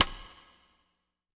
BW STICK01-L.wav